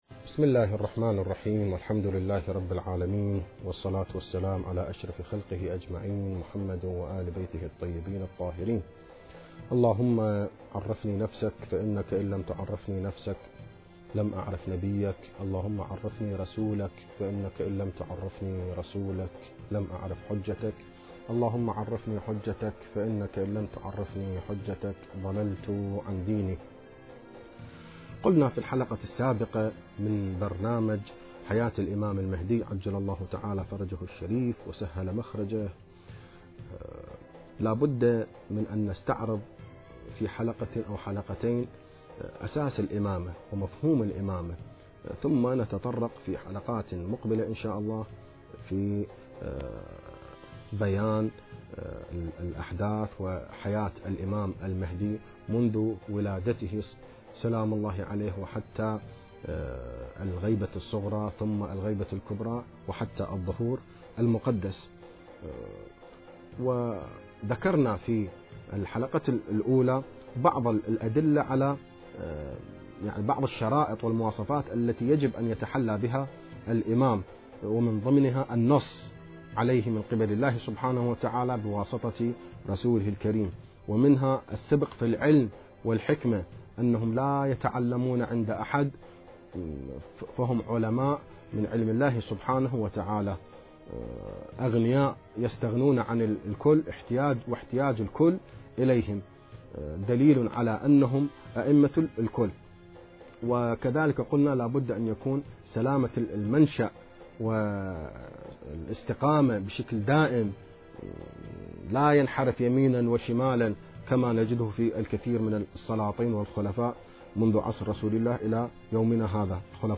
دروس من حياة الإمام المهدي عليه السلام (٢)
المكان: اذاعة الفرات